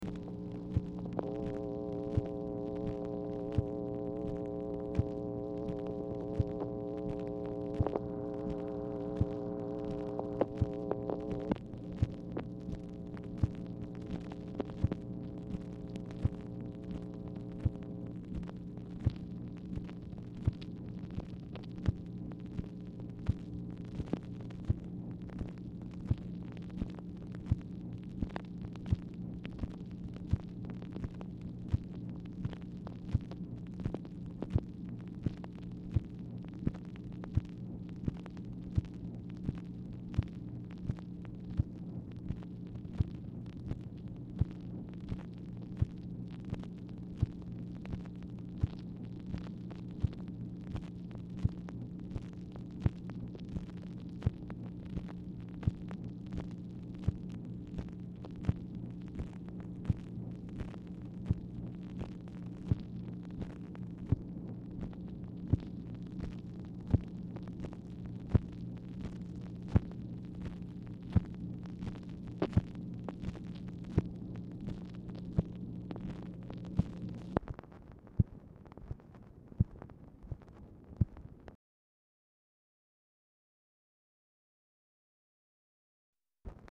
Telephone conversation # 7568, sound recording, MACHINE NOISE, 5/4/1965, time unknown | Discover LBJ
Format Dictation belt
Specific Item Type Telephone conversation